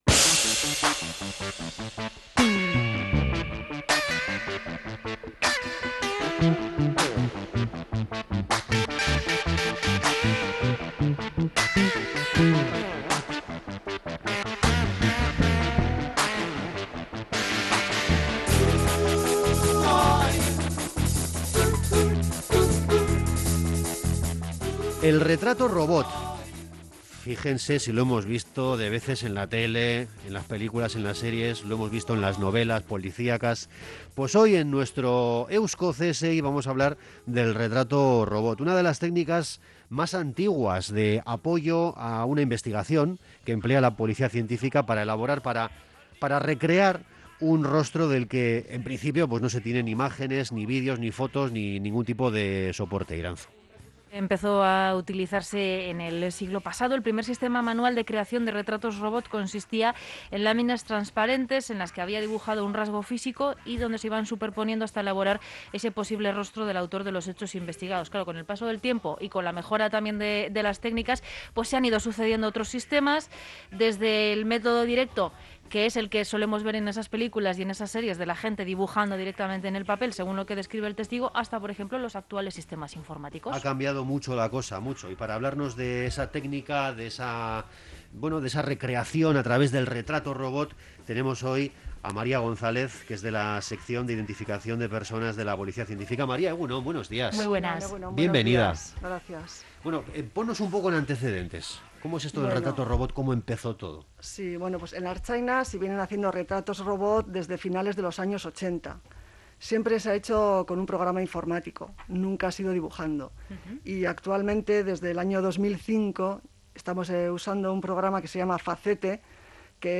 Morning show conectado a la calle y omnipresente en la red.
El retrato robot es una de las técnicas más antiguas de apoyo a la investigación empleadas por la Policía Científica para elaborar un rostro del que no se tienen imágenes. Hablamos con